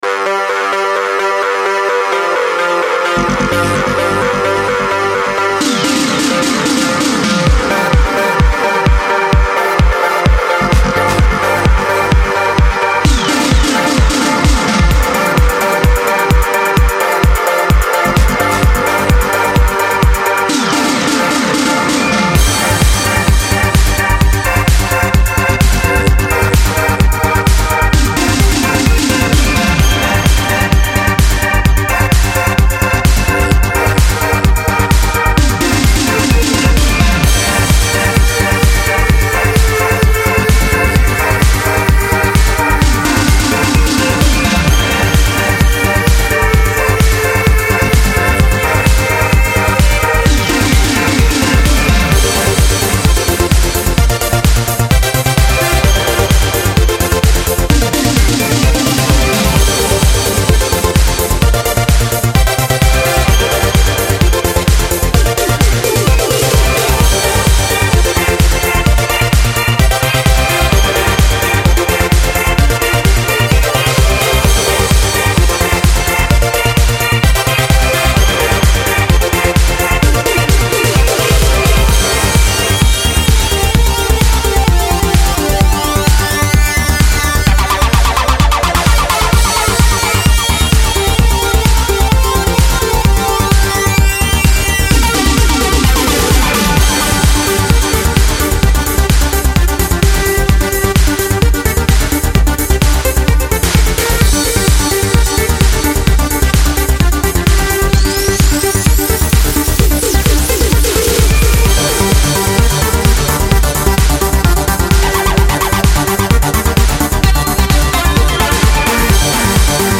Genre: Synthpop, Spacesynth, Electronic, Trance, House.